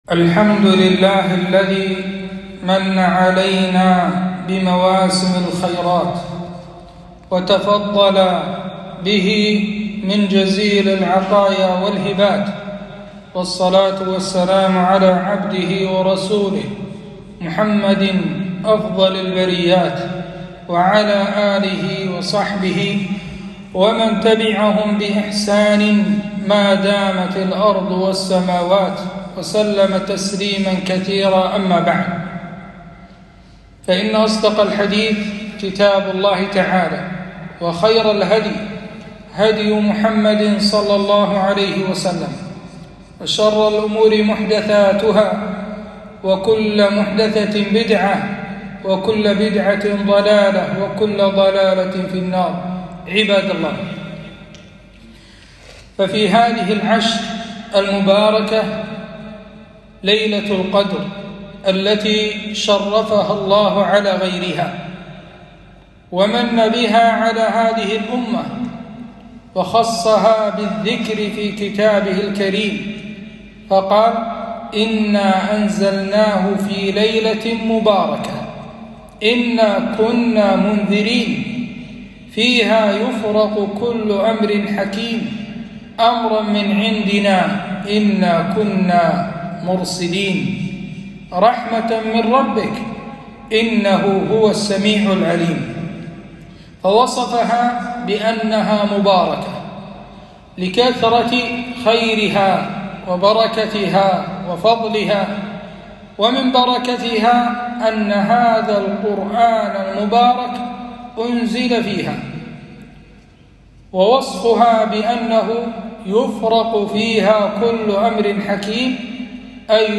خطبة - { إنا أنزلناه في ليلة القدر }